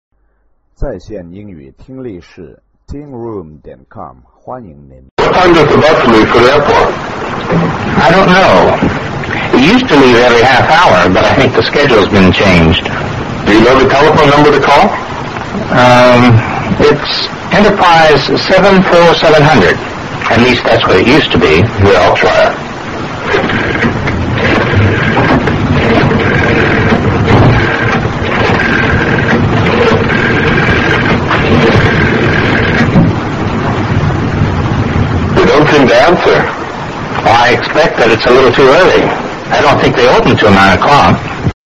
在线英语听力室AIRPORT BUS的听力文件下载,英语经典听力对话-在线英语听力室